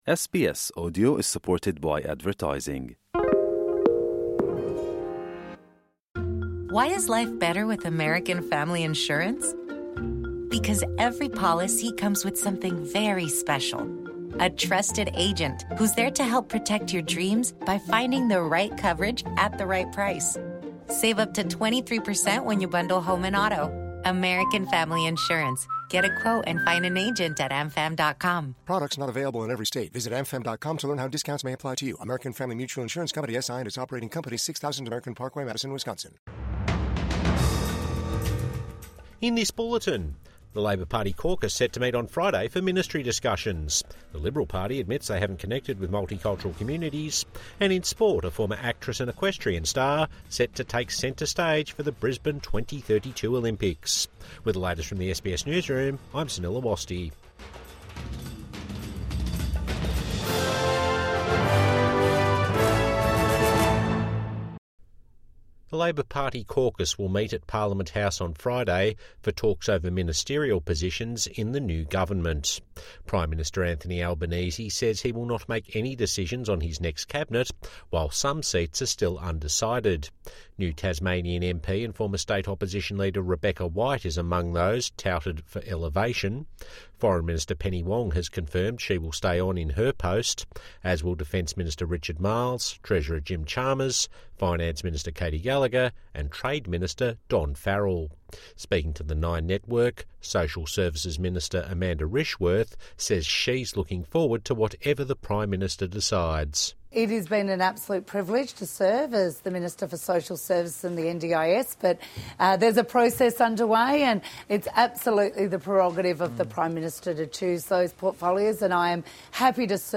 Liberal Party admits they haven't connected with multicultural Australia | Evening News Bulletin 6 May 2025 | SBS News